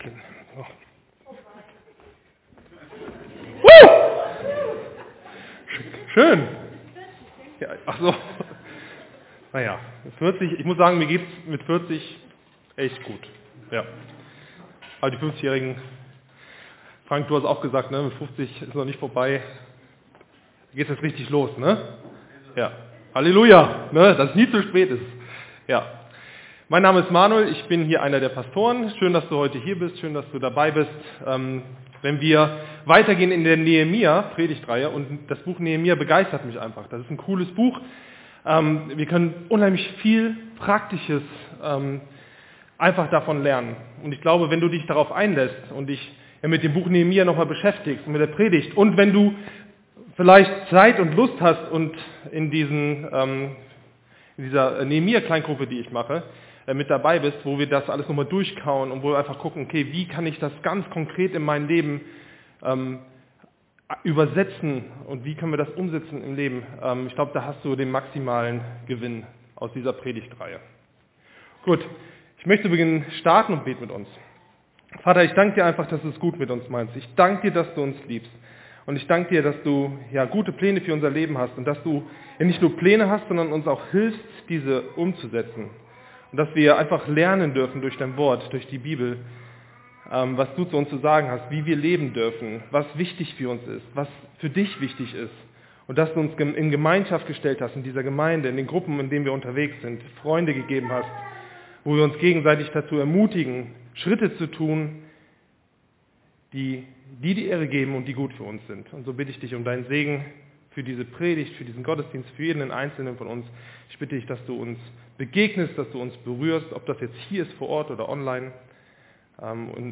Mutig voran gehen | Gottesdienst